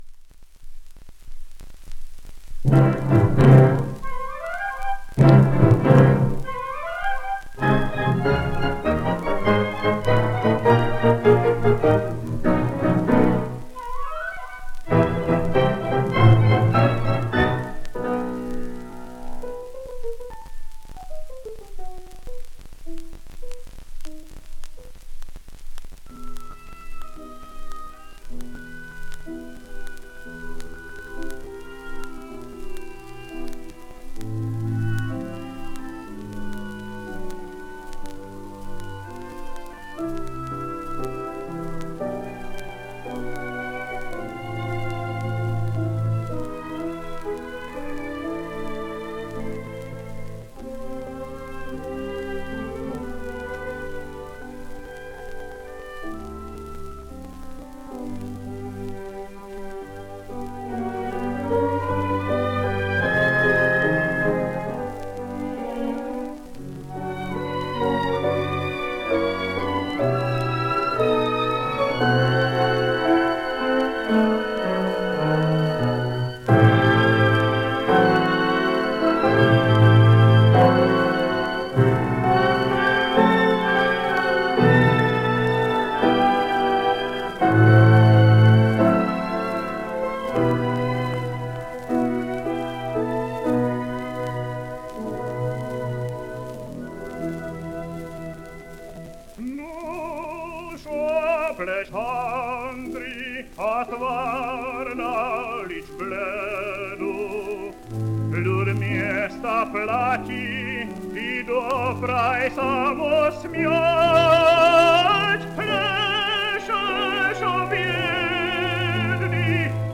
voice